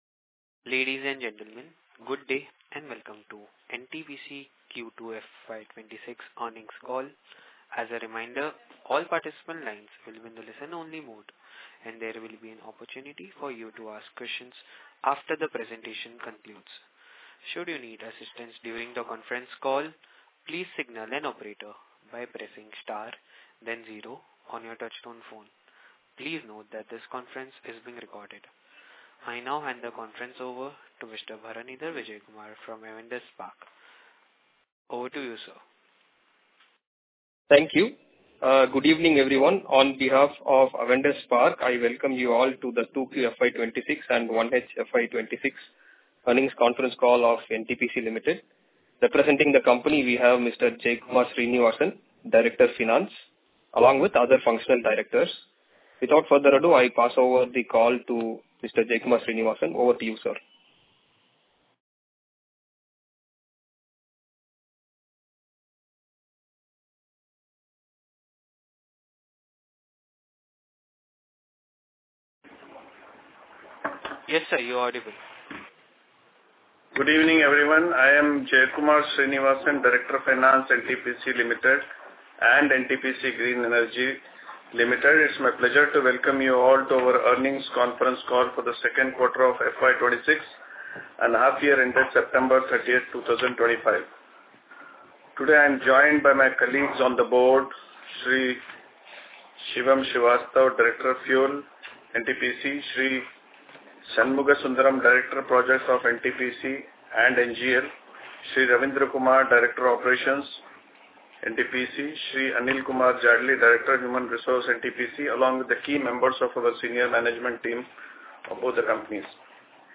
Conference call with Analysts & Investors | NTPC Limited